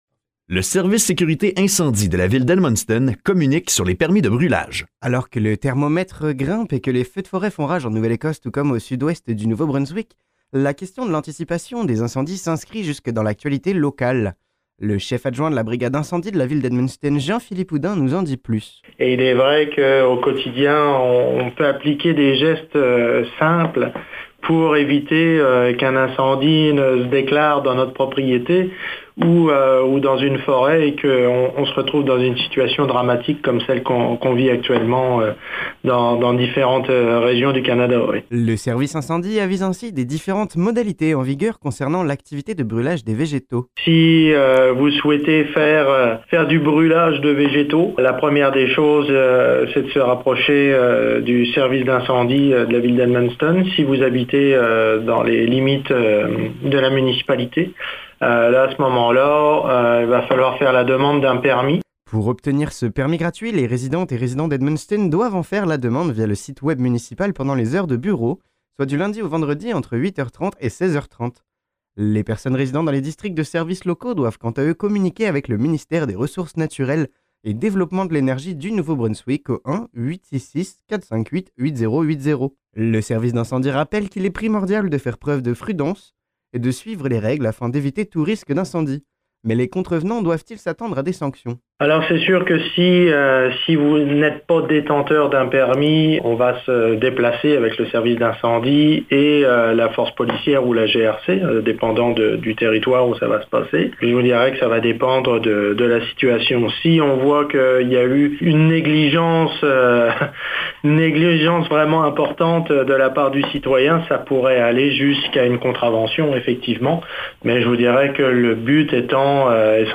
Le bulletin